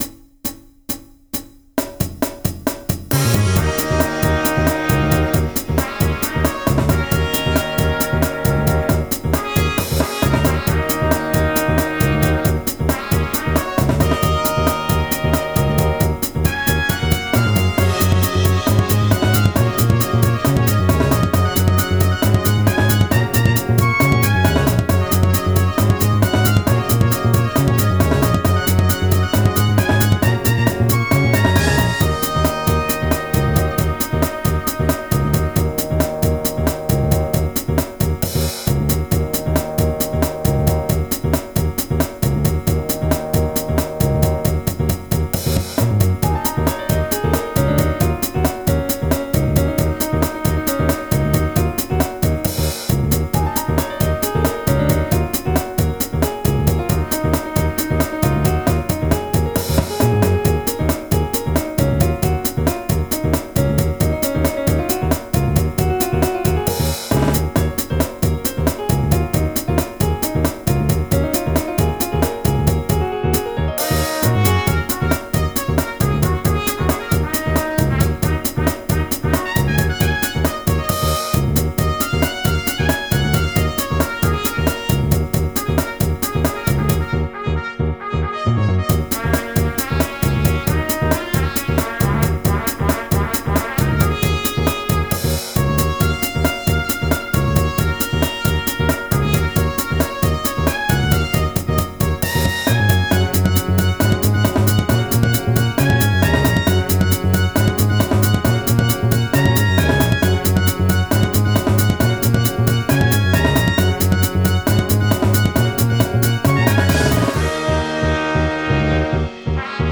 BGM
ロング明るい民族激しい